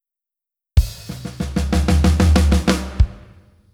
この例ではフィルイン用のMIDIファイルを再生しています。
再生されたFill inのサウンドをPro Toolsで録音し、waveファイルに書き出しました。